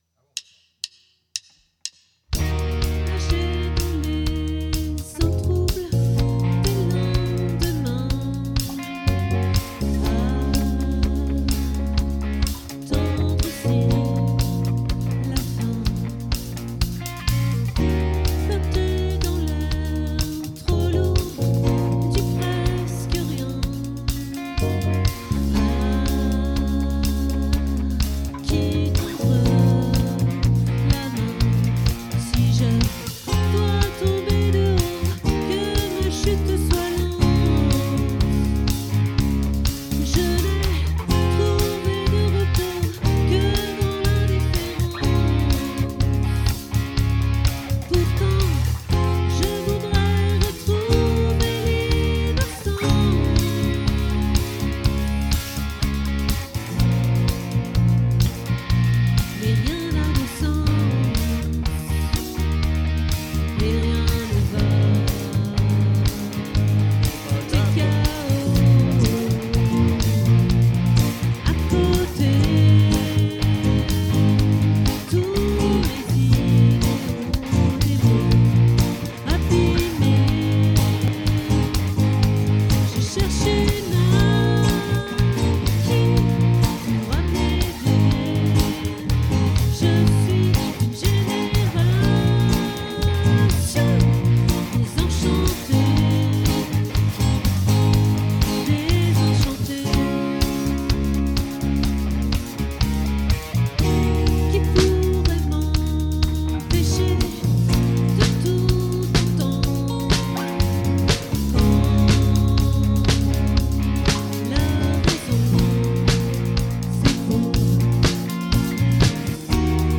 🏠 Accueil Repetitions Records_2026_01_12